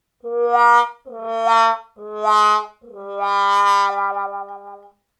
trombone.mp3